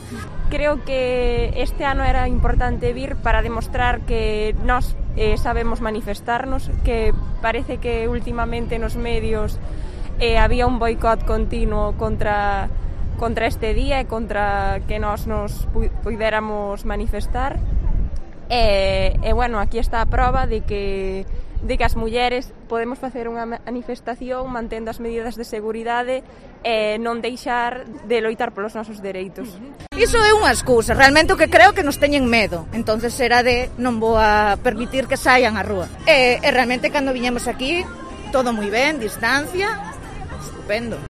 Declaraciones de mujeres participantes en la concentración del 8 de marzo de Pontevedra